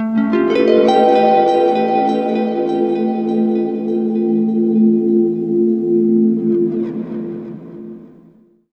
GUITARFX16-R.wav